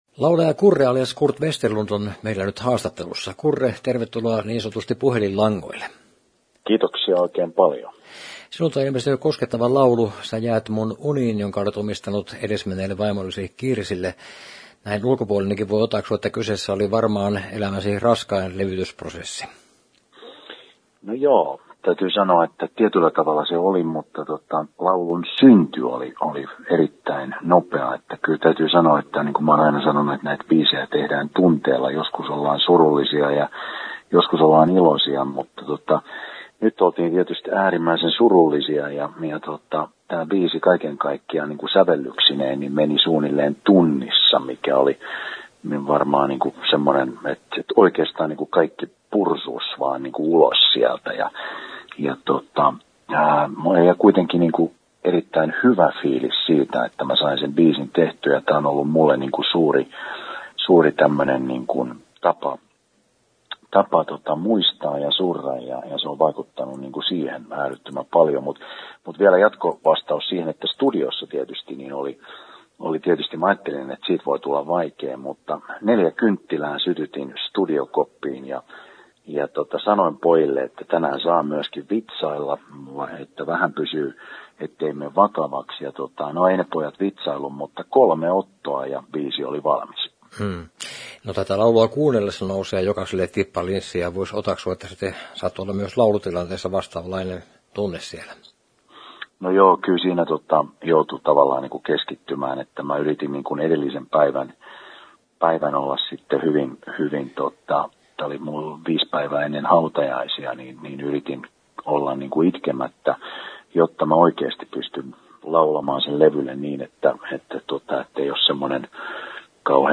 Haastattelu, Henkilökuvassa, Viihdeuutiset, Yleinen